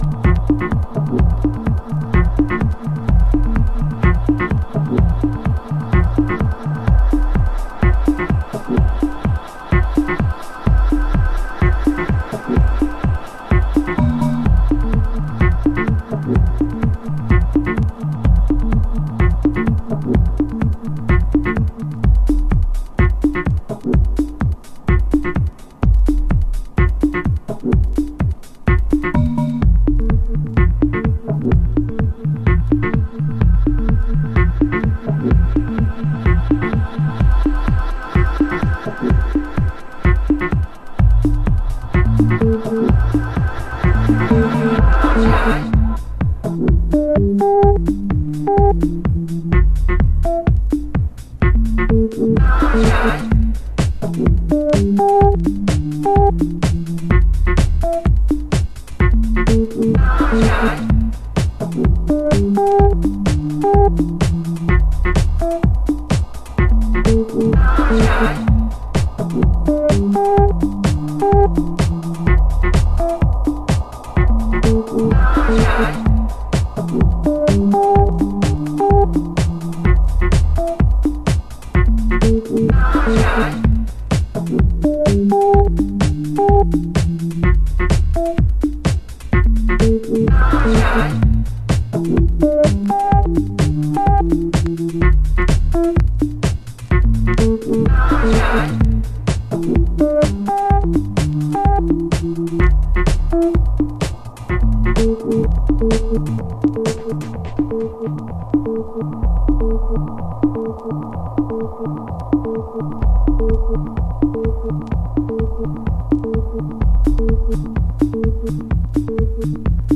隙間重視のブギーグルーヴにポップにひねたシンセがうねり続ける